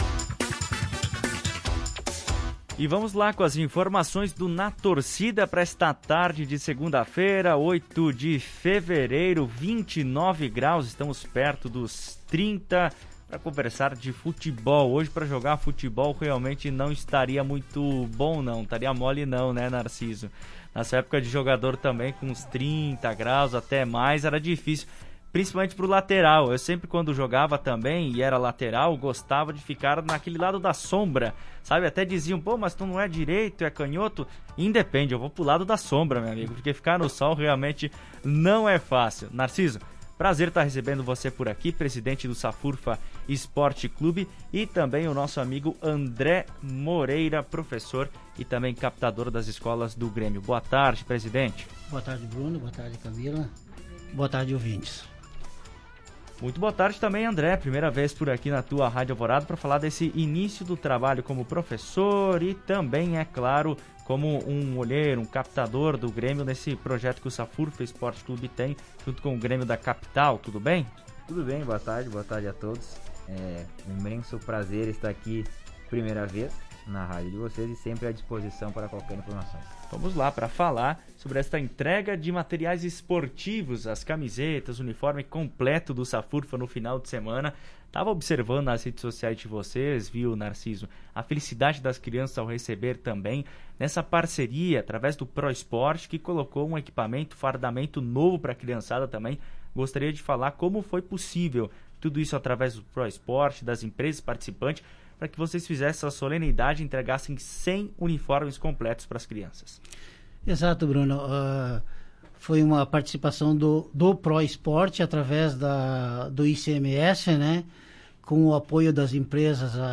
A entrevista completa